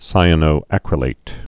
(sīə-nō-ăkrə-lāt, sī-ănō-)